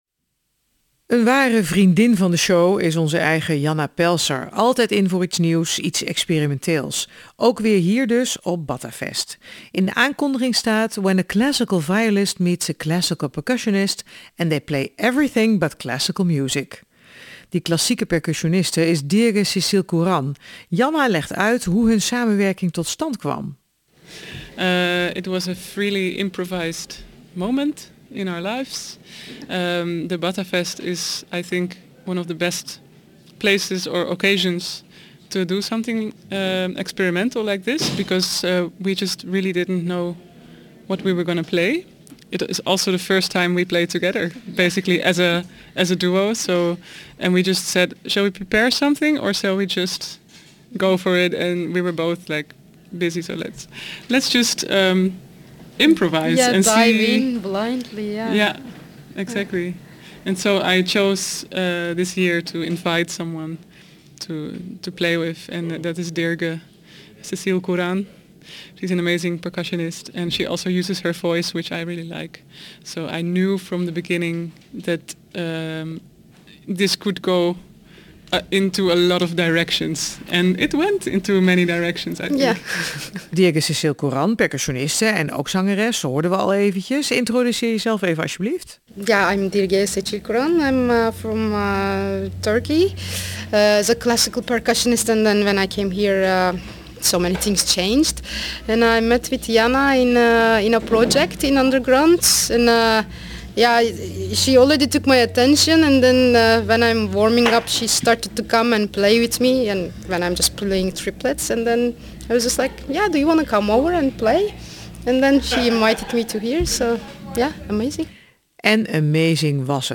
In deze aflevering nemen we je opnieuw mee naar het Batavierhuis in Rotterdam, voor het tweede deel van de opnames die we tijdens Batafest 2025 in oktober gemaakt hebben.